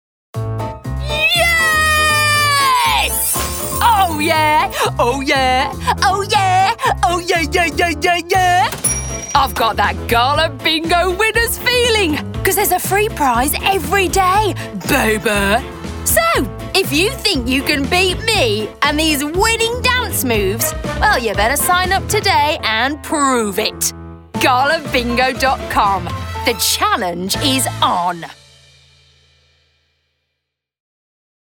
Voice Reel
Gala Bingo - Energetic, Charismatic, Fun
Gala Bingo - Energetic, Charismatic, Fun.mp3